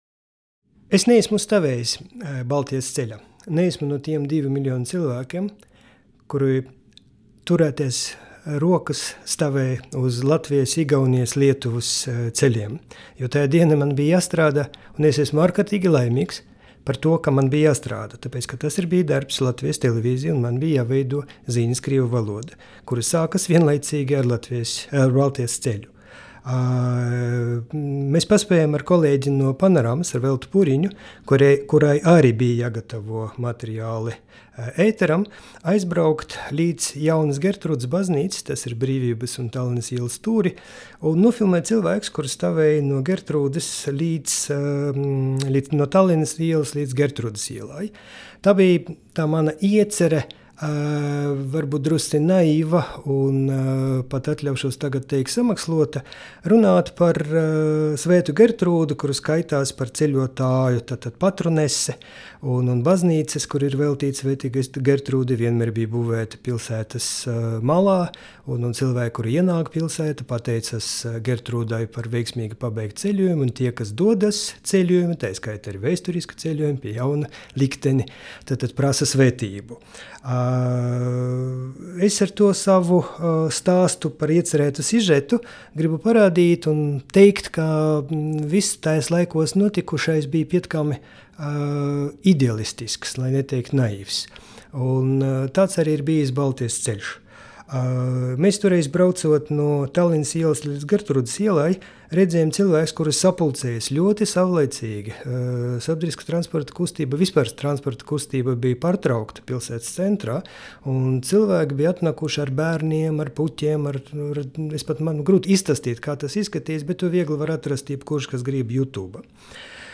Latvijas Nacionālās bibliotēkas audio studijas ieraksti (Kolekcija)